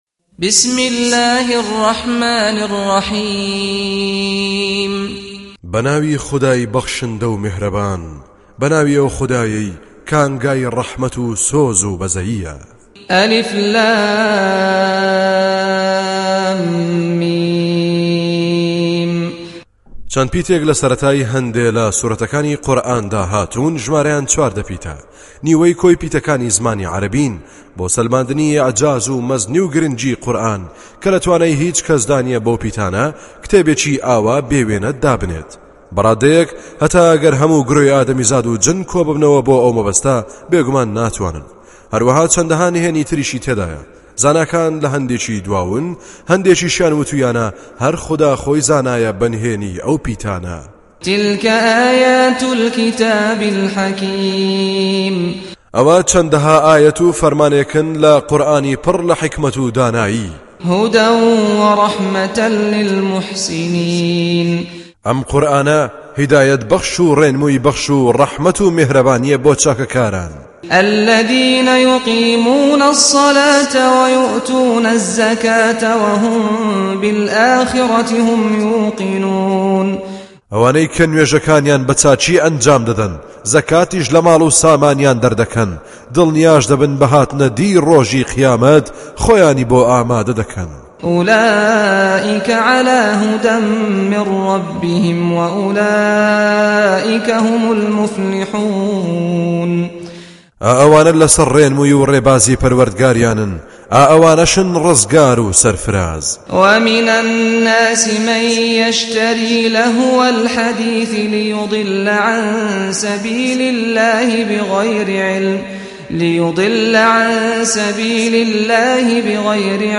Surah Sequence تتابع السورة Download Surah حمّل السورة Reciting Mutarjamah Translation Audio for 31. Surah Luqm�n سورة لقمان N.B *Surah Includes Al-Basmalah Reciters Sequents تتابع التلاوات Reciters Repeats تكرار التلاوات